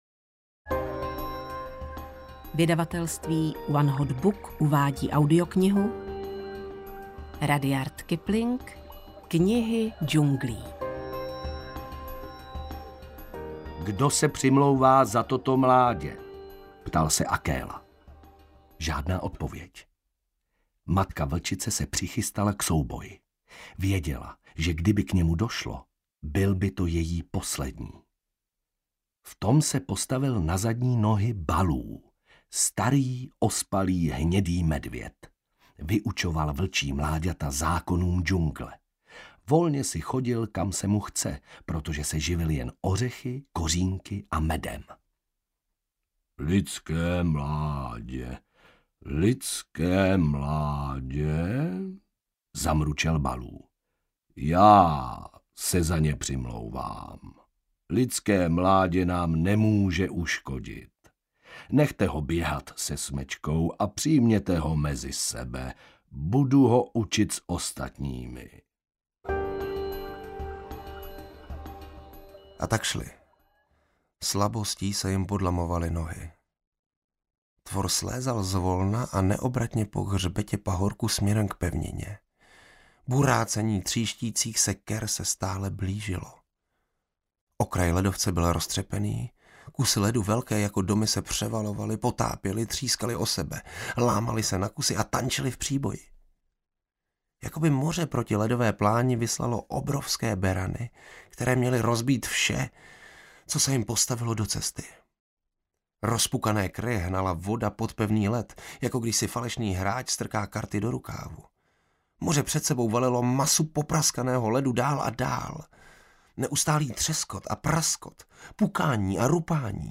Knihy džunglí audiokniha
Ukázka z knihy